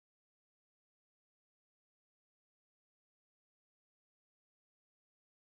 SILENCE256
silence256.mp3